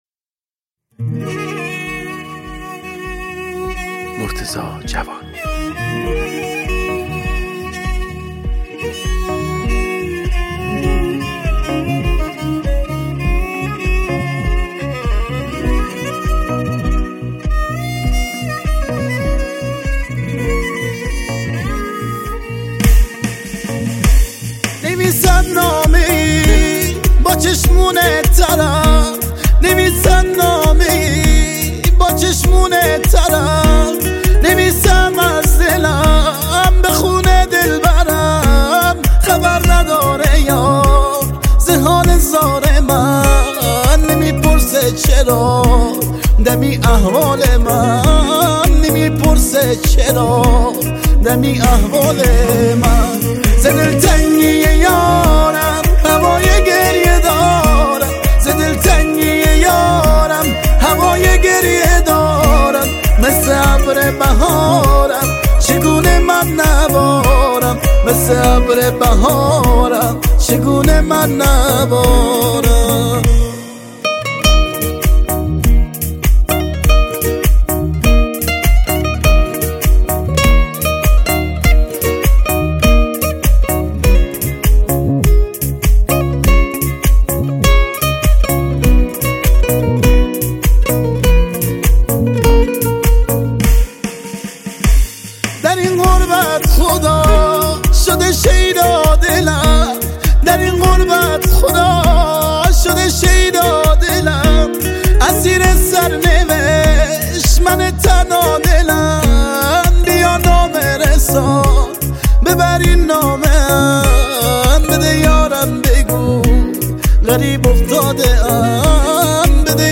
نسخه تک خوانی